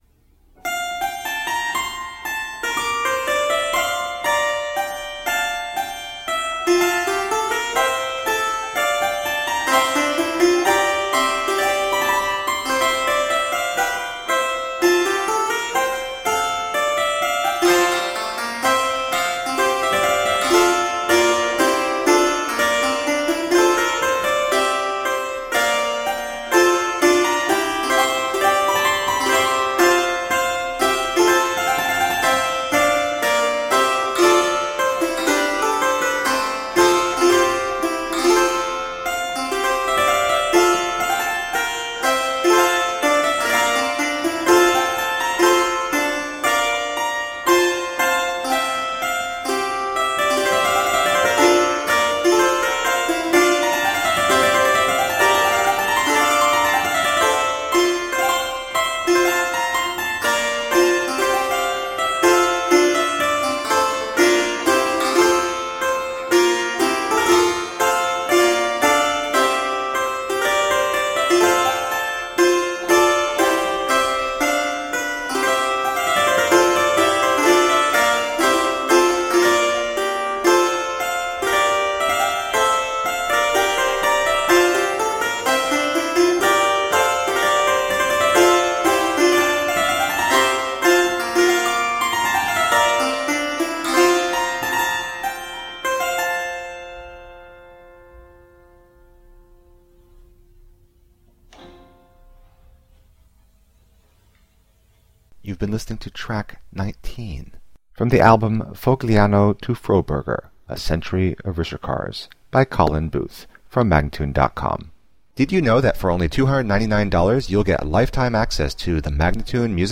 Solo harpsichord music
Classical, Baroque, Instrumental Classical
Harpsichord